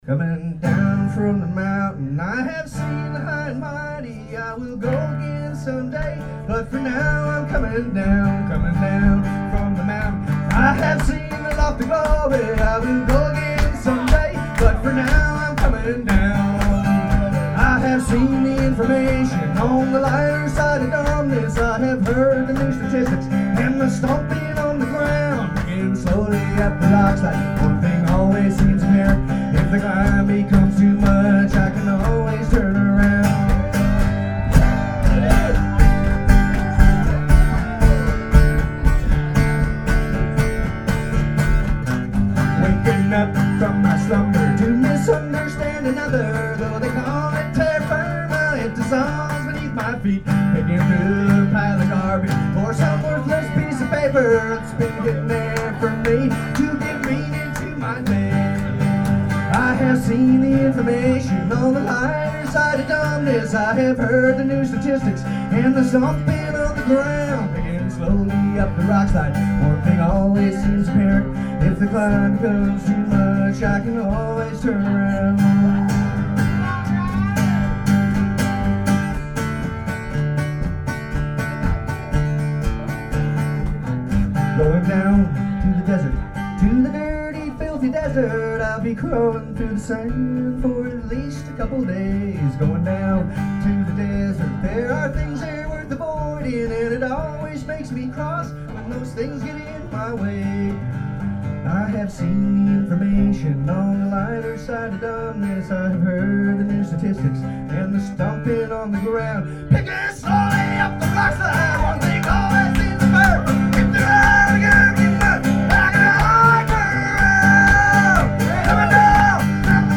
Well, this show was a blast.